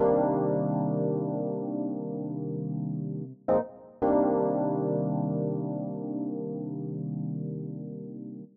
06 ElPiano PT2.wav